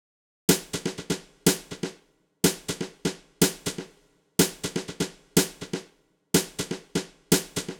08 Snare.wav